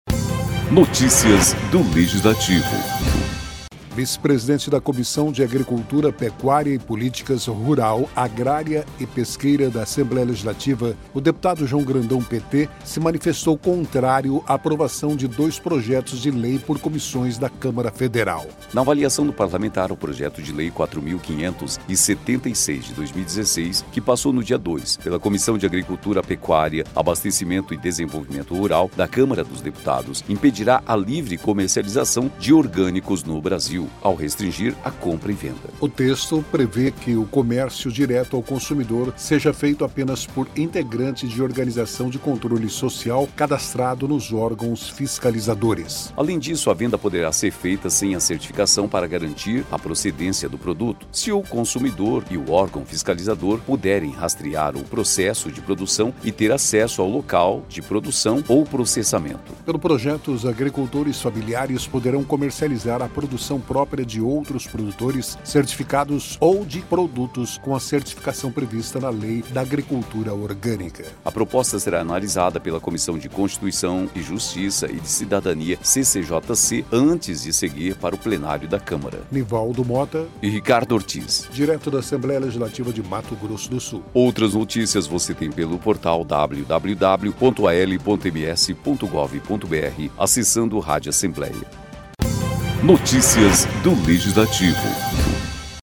“É um absurdo, lamentável. Sabemos que já há produção em maior escala, mas em geral quem responde pelos orgânicos são os pequenos produtores, que serão prejudicados também”, disse, durante a sessão ordinária desta terça-feira (3).